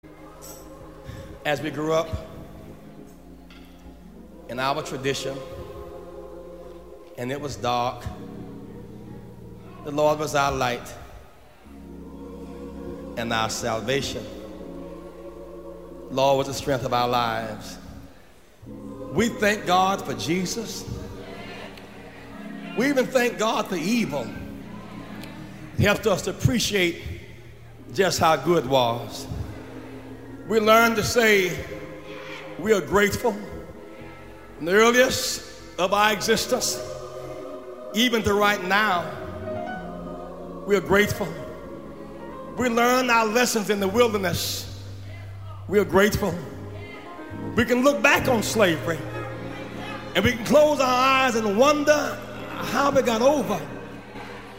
Short Message、Jesse Louis Jackson,Sr.
July 27,28,30,1987 Detroit,ARISTA A40CD-1(CD)